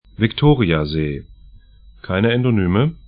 Victoriasee vɪk'to:rĭaze: